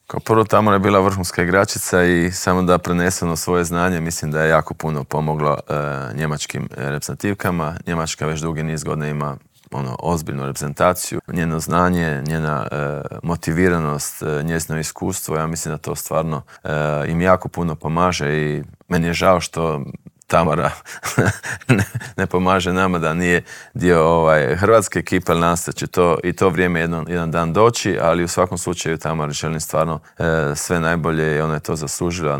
U dvorani Krešimira Ćosića na Višnjiku očekuje nas ekipno prvenstvo Europe, a u Intervjuu tjedna Media servisa ugostili smo predsjednika Hrvatskog stolnoteniskog saveza Zorana Primorca